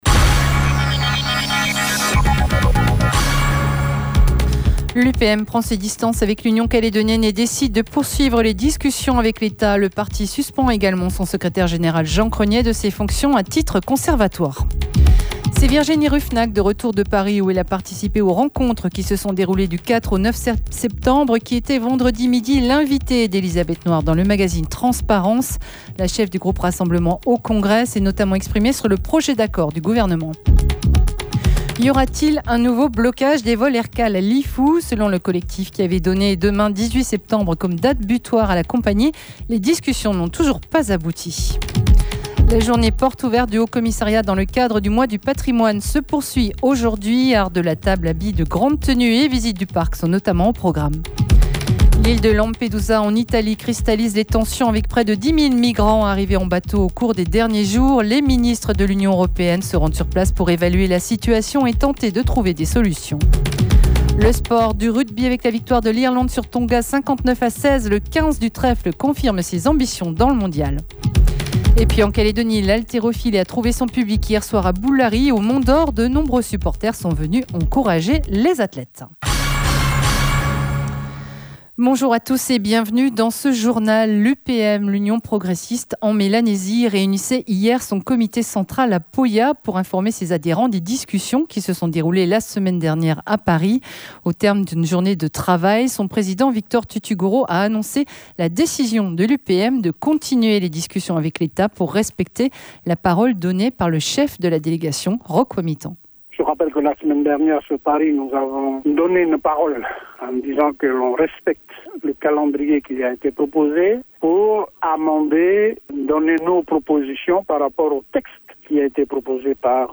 JOURNAL : INFO WEEK END DIMANCHE MIDI